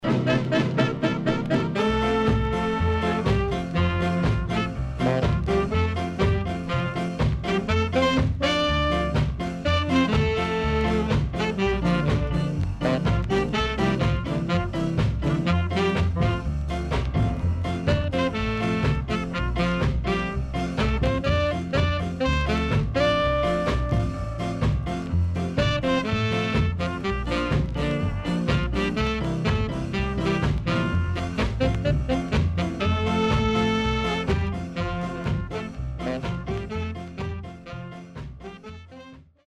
SIDE A:少しノイズ入りますが良好です。